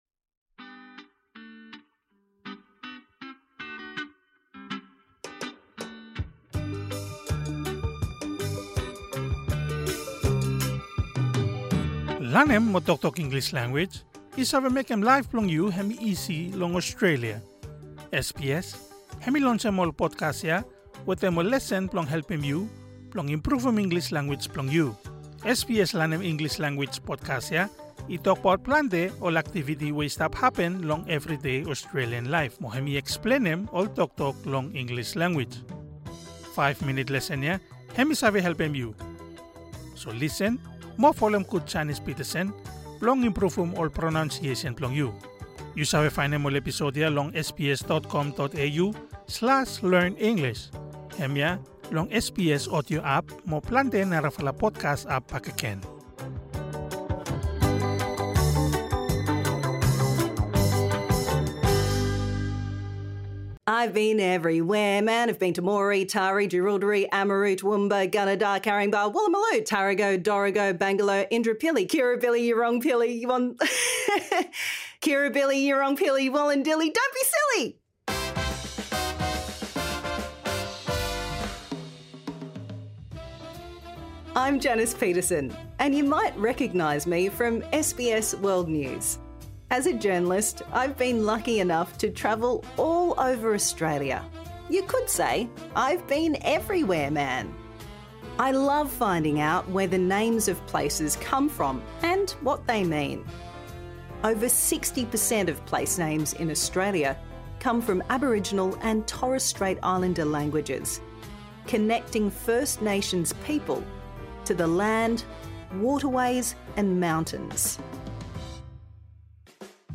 Wanem we yumi wantem lanem hemi blong pronouncem cu: /uː/ and /əʊ/ (flu mo flow)
Improve your pronunciation | Season 2